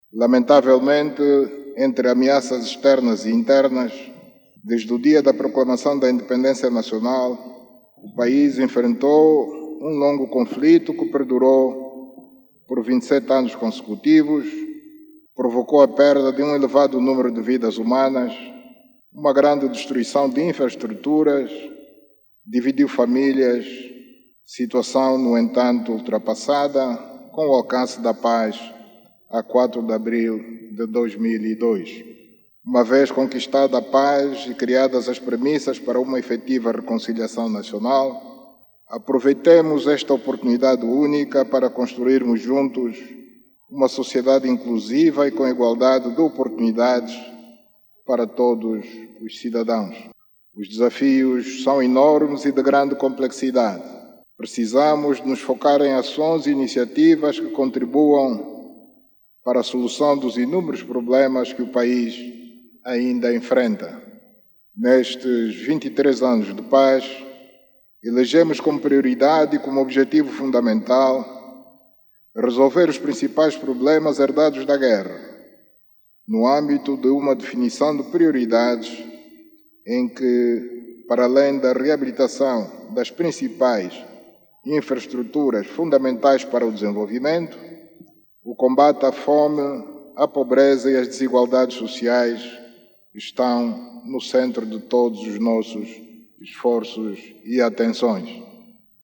O Presidente da República, João Lourenço, durante a sua mensagem dirigida à Nação, proferida na Praça da República, por ocasião das comemorações dos 50 anos da Independência Nacional, reafirmou o compromisso do Executivo em continuar a investir no sector da Educação, com o objectivo de reduzir o número de crianças fora do sistema de ensino e combater o analfabetismo no país. João Lourenço anunciou ainda que a formação de formadores e professores continuará a ser uma das grandes prioridades do Executivo.